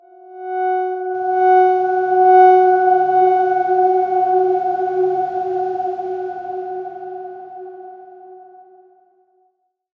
X_Darkswarm-F#4-mf.wav